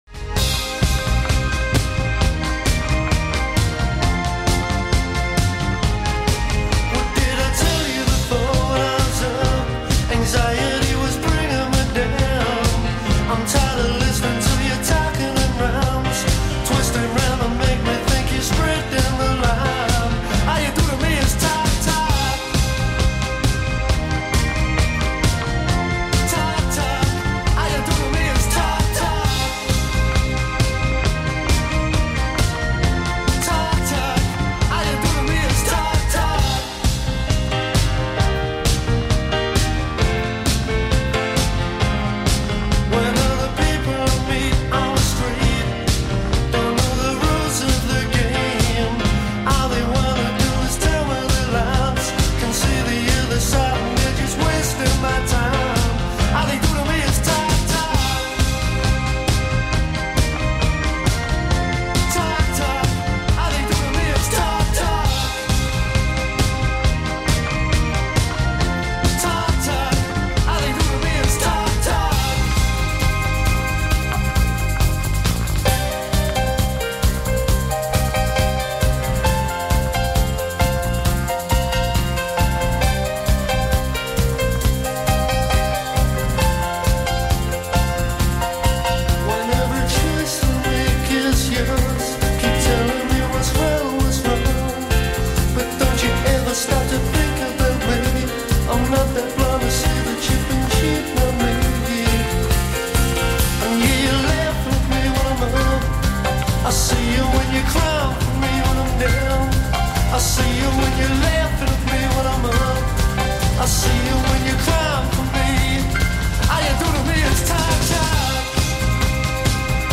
New Wave